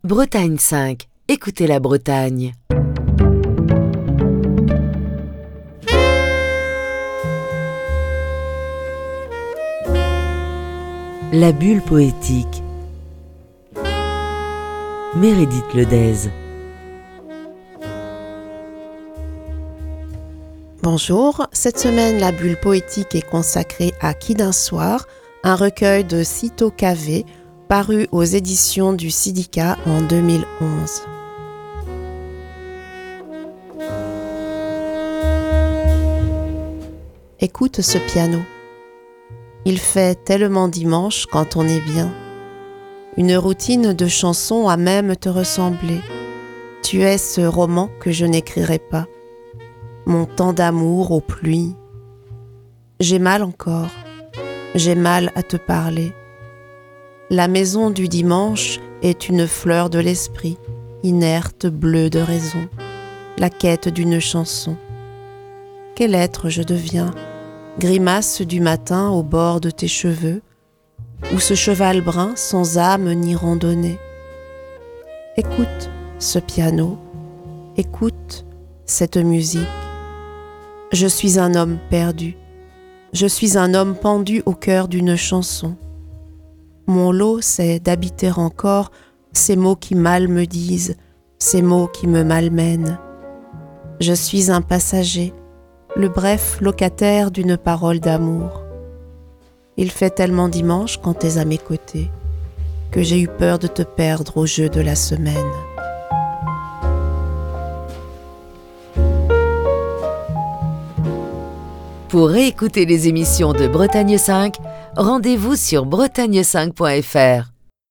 lit quelques poèmes du poète haïtien Syto Cavé, extraits de son recueil "Qui d'un soir ?", paru aux éditions du CIDIHCA en 2011.